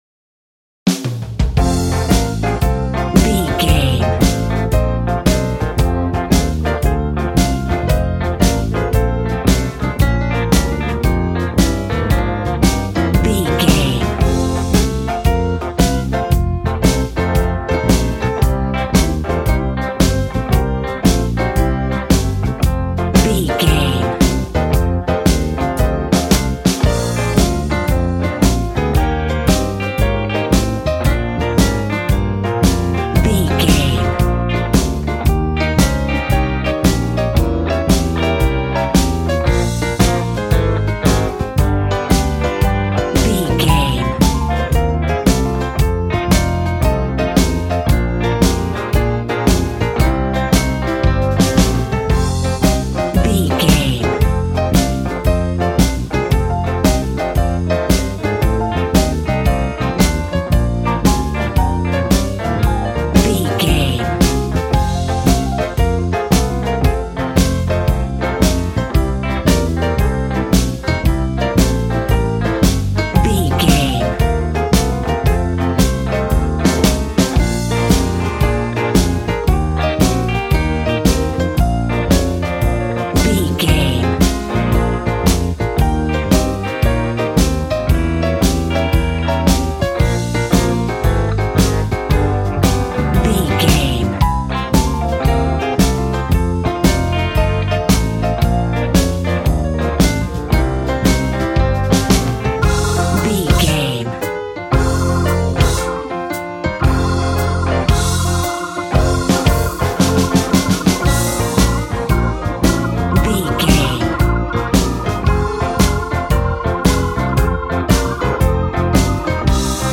Ionian/Major
hard
bass guitar
electric guitar
electric organ
drums